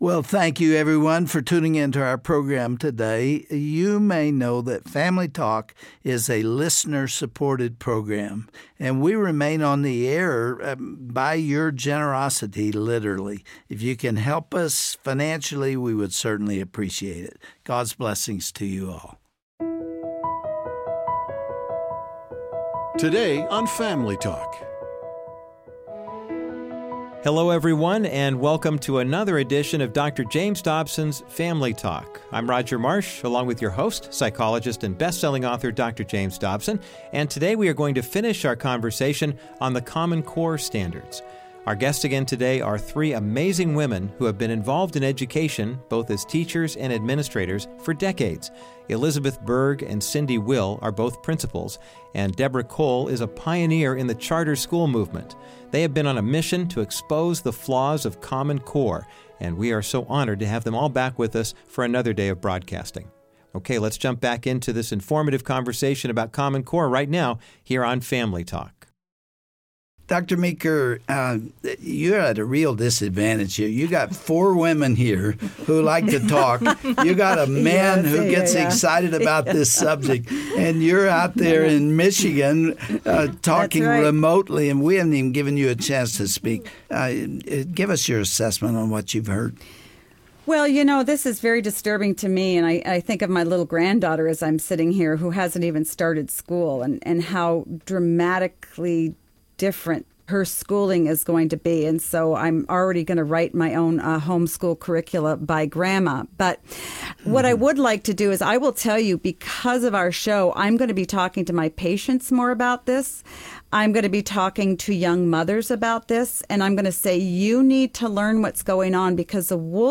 Isnt the government just trying to make sure our kids get a good education? On the next edition of Family Talk, Dr. James Dobson interviews a panel of administrators about the problem with common core.